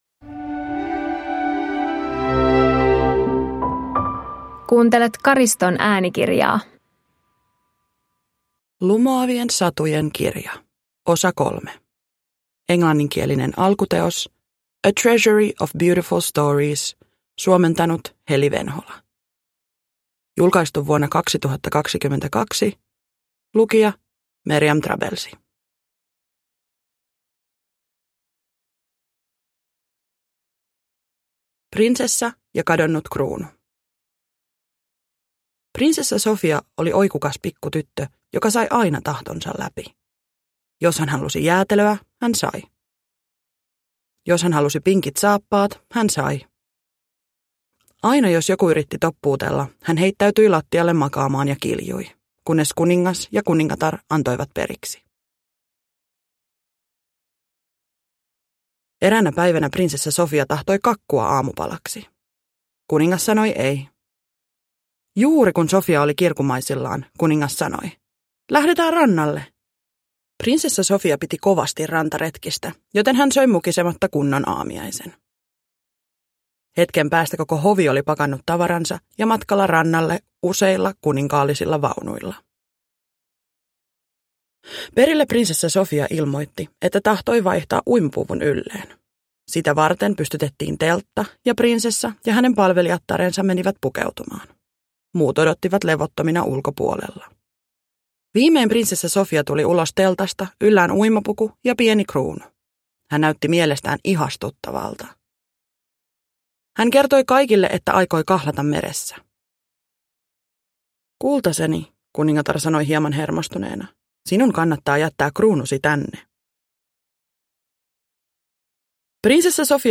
Lumoavien satujen kirja 3 – Ljudbok – Laddas ner